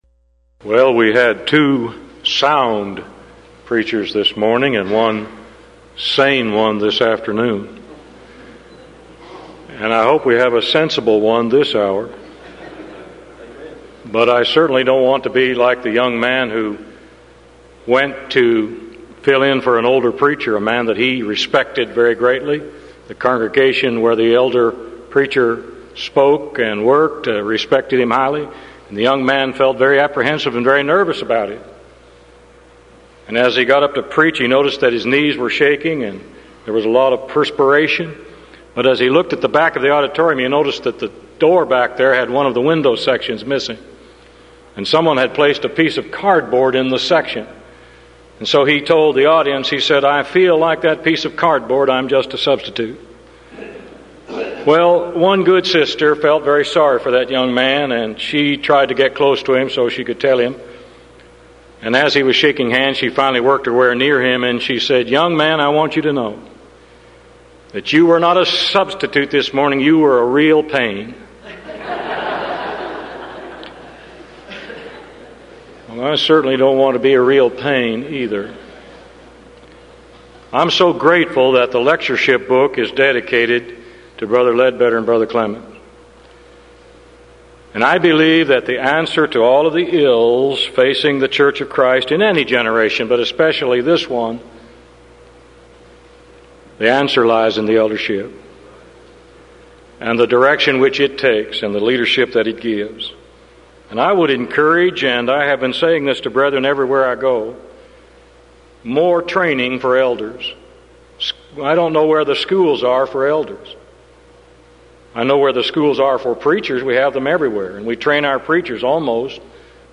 Series: Denton Lectures Event: 1994 Denton Lectures